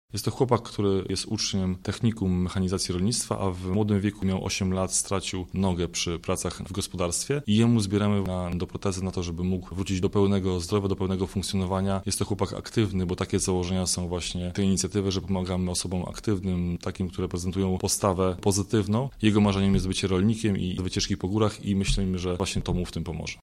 • mówi